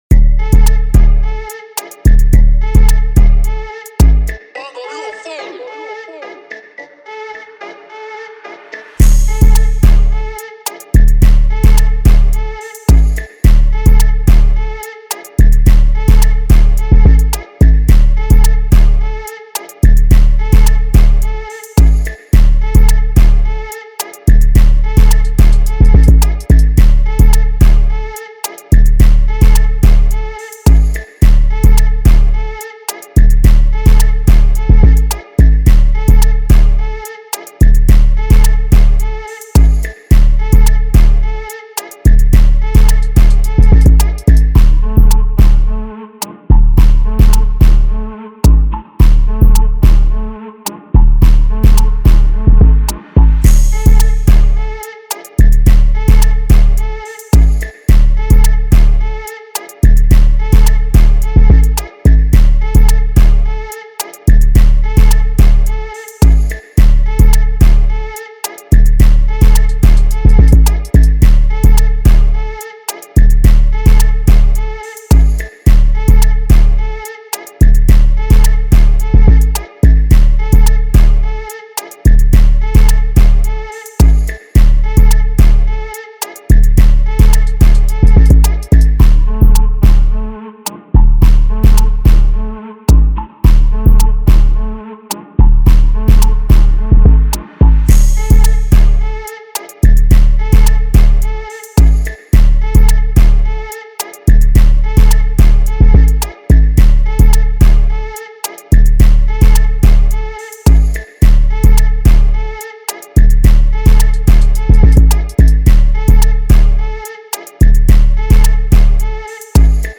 official instrumental
West Coast Instrumentals